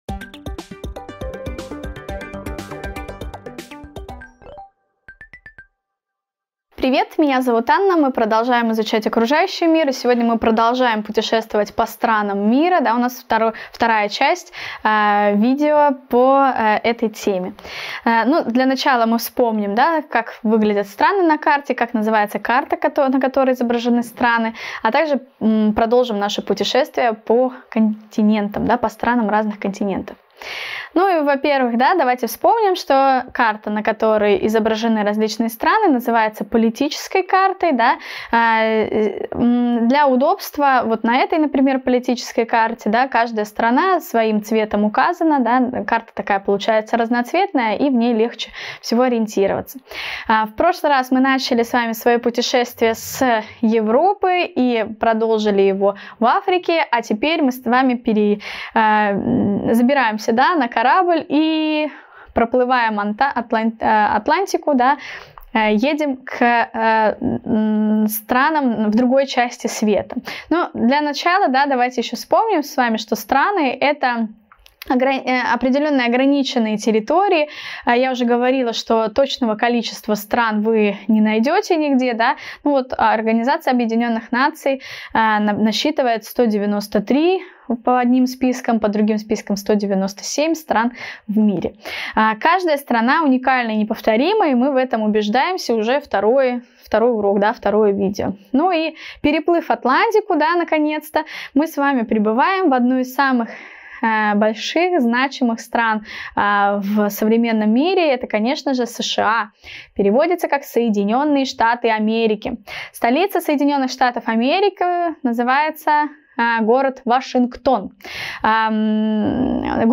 Страны мира Часть 2 Видеоурок 332 Окружающий мир 2 клас�